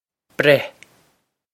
Breith Breh
This is an approximate phonetic pronunciation of the phrase.